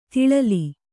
♪ tiḷali